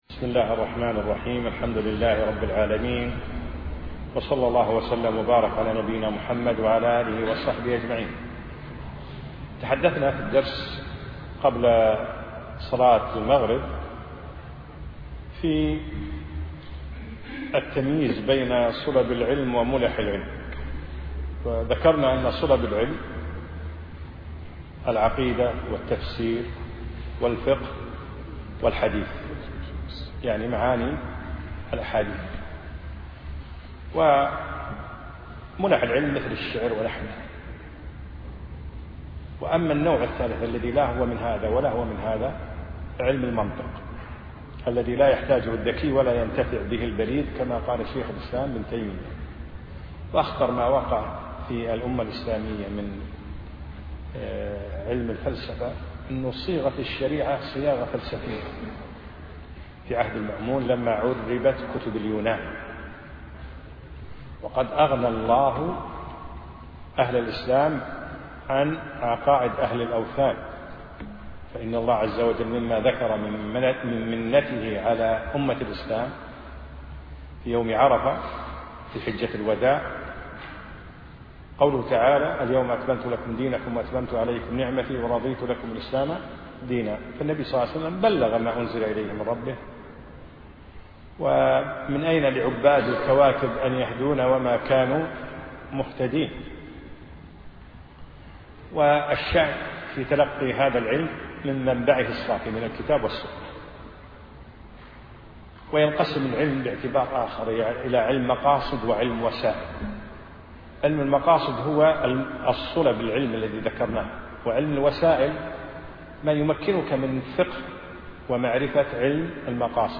أقيمت الدورة في دولة الإمارات
الدرس الأول